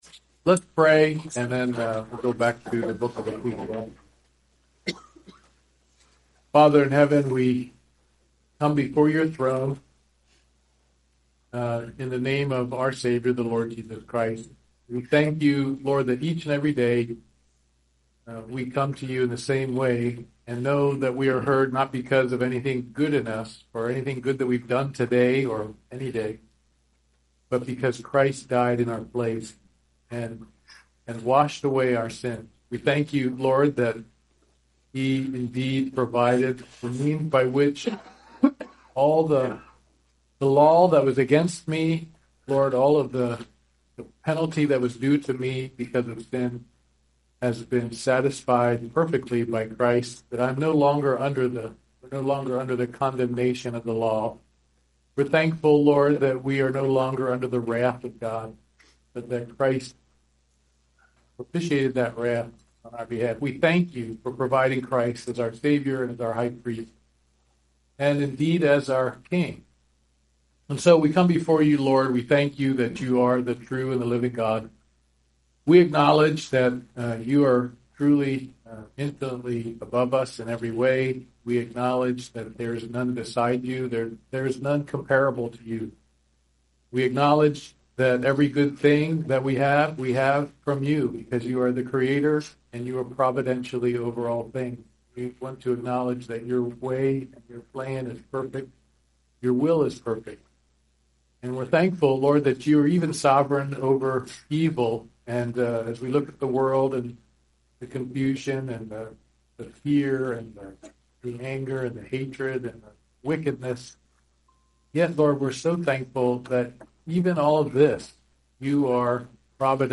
NOTE: The audio quality in this message is degraded from the 10:32.5 point to the 18:08.6 point.
Ecclesiastes 9:13-15 Service Type: Wednesday Morning Bible Study NOTE